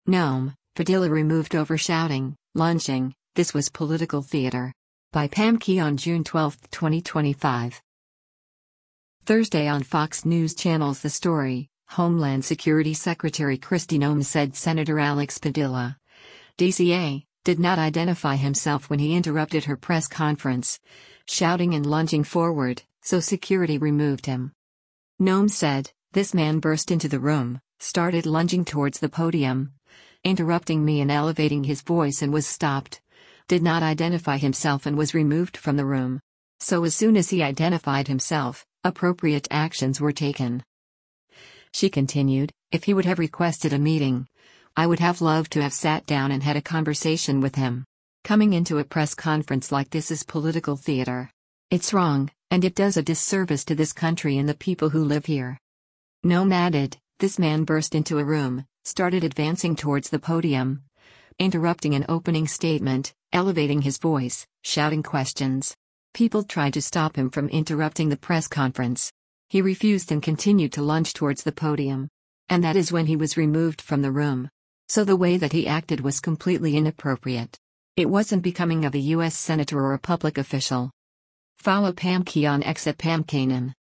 Thursday on Fox News Channel’s “The Story,” Homeland Security Secretary Kristi Noem said Sen. Alex Padilla (D-CA) did not identify himself when he interrupted her press conference, shouting and lunging forward, so security removed him.